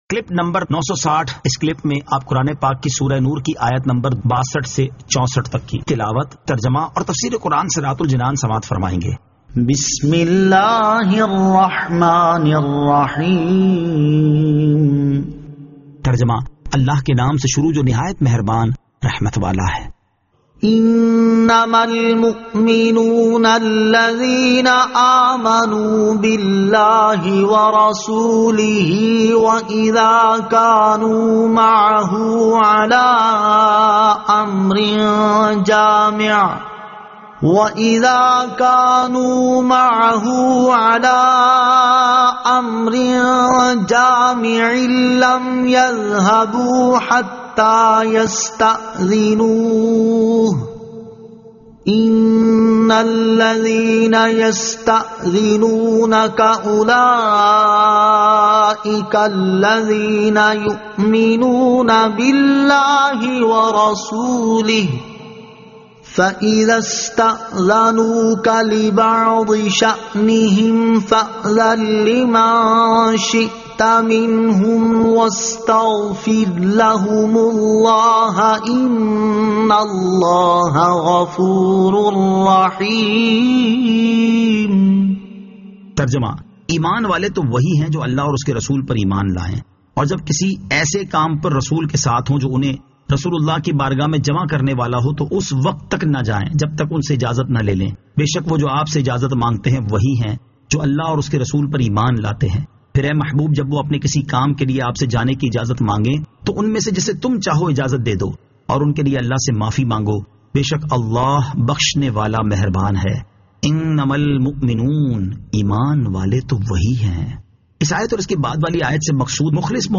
Surah An-Nur 62 To 64 Tilawat , Tarjama , Tafseer